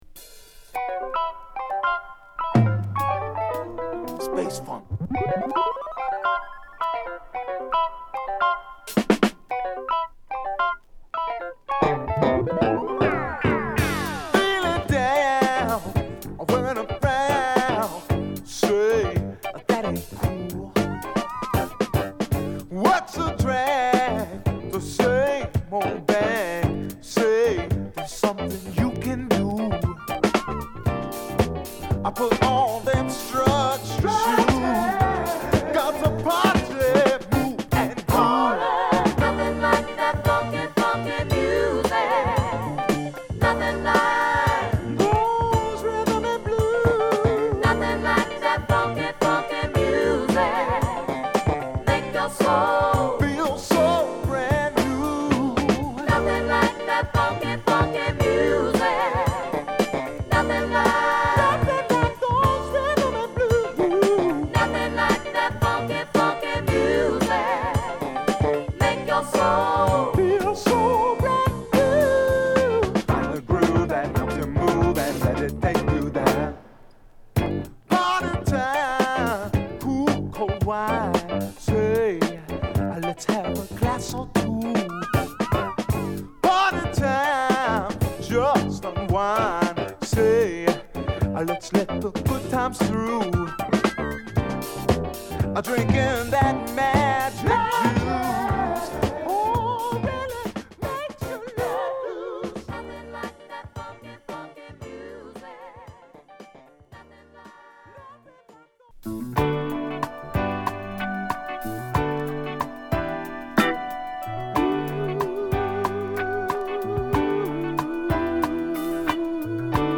ハワイ産レアグルーヴ